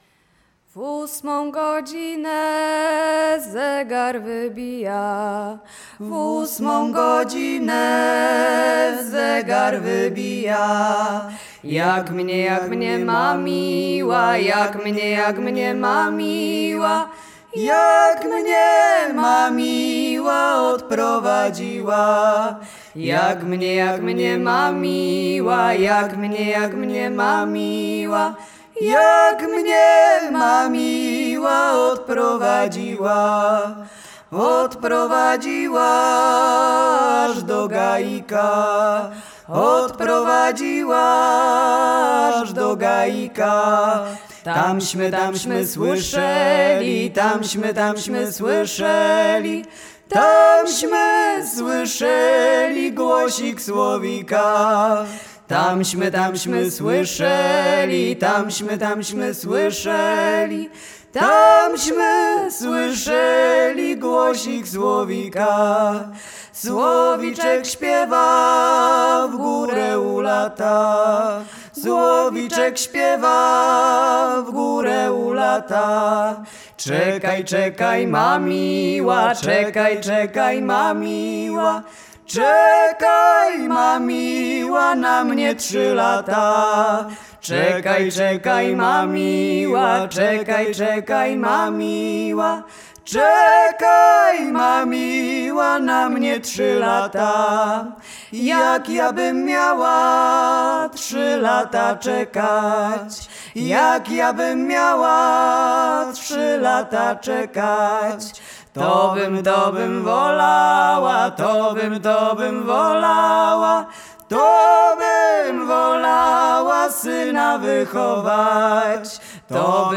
Zespół Łada
rekruckie wojenkowe